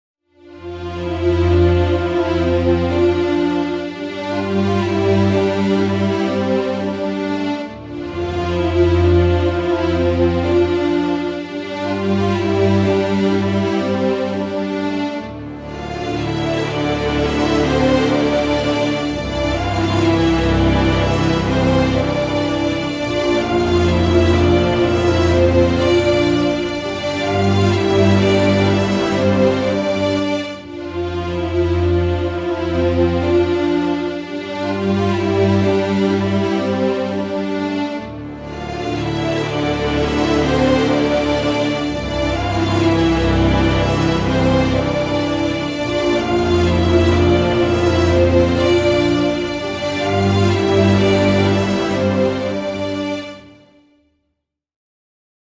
tema dizi müziği, duygusal heyecan gerilim fon müziği.